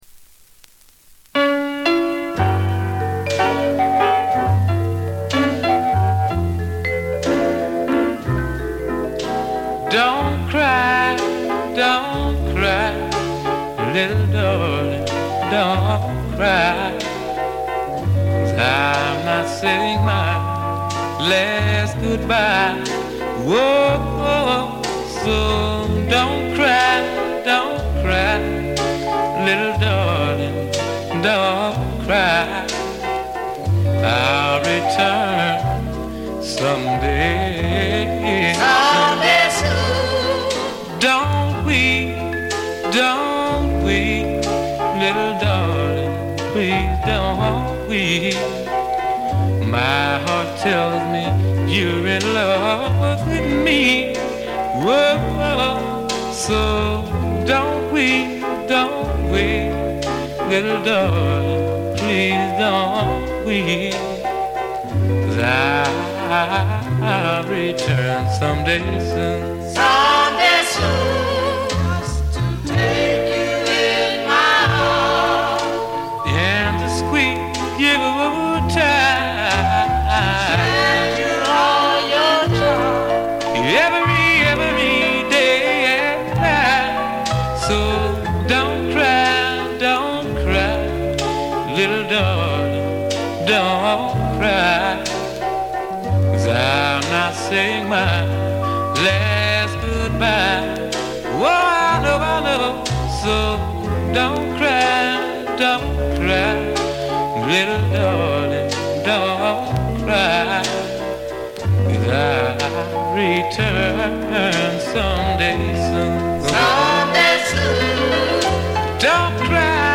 静音部で微細なバックグラウンドノイズが聴かれる程度。
モノラル盤。
試聴曲は現品からの取り込み音源です。